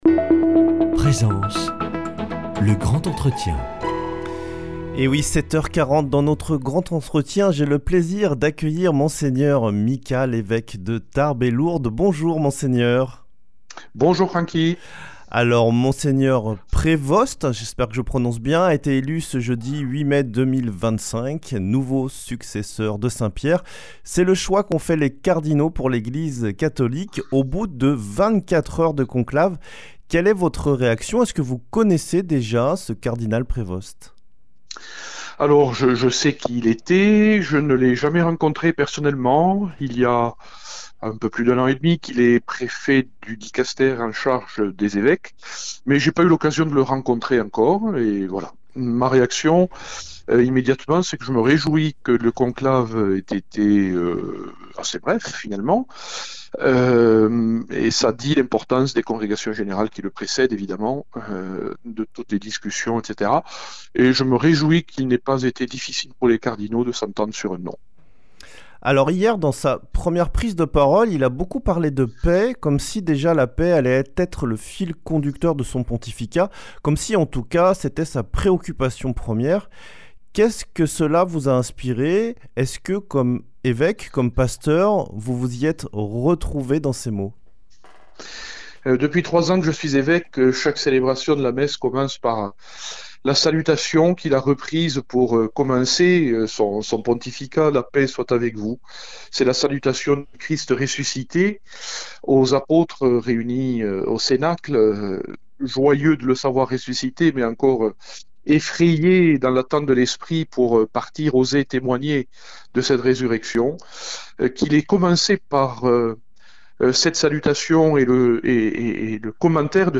Le 8 mai 2025, l’Église catholique a reçu un nouveau pasteur : le cardinal Robert Prevost, devenu le pape Léon XIV. Le lendemain, Monseigneur Jean-Marc Micas, évêque de Tarbes et Lourdes, a partagé au micro de Radio Présence ses premières impressions à la lumière des paroles du nouveau pape. Mgr Micas confie aussi ce qu’il espère pour l’Église, pour Lourdes, et pour son diocèse.